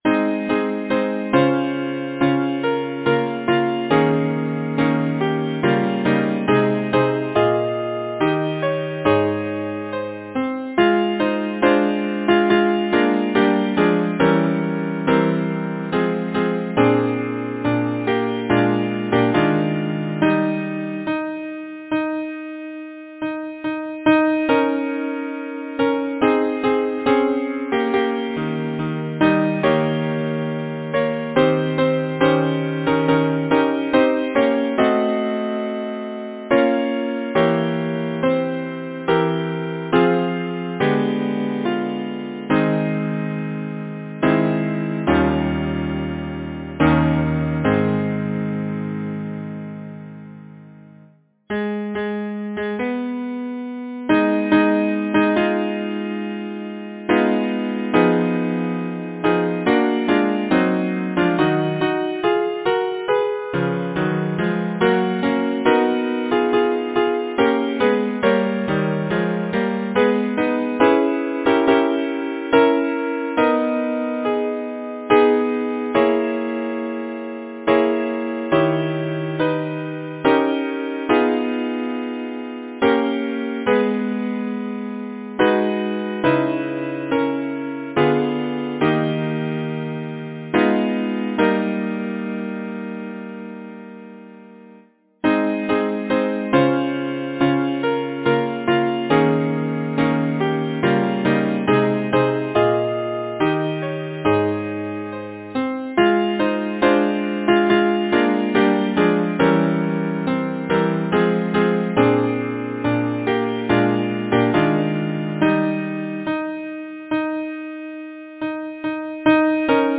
Title: She walks in beauty Composer: Henry Elliot Button Lyricist: George Gordon Byron Number of voices: 4vv Voicing: SATB Genre: Secular, Partsong
Language: English Instruments: A cappella